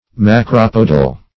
Macropodal \Ma*crop"o*dal\, a. Having long or large feet, or a long stem.